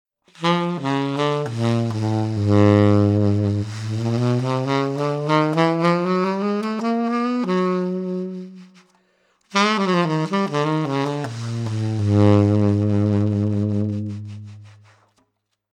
Imboccature sax tenore
Di poco più scuro del modello Roma, è progettato per quei saxofonisti che ricercano un suono possente, molto proiettivo e non eccessivamente chiaro.
Ten-Florence-bronze-subtone.mp3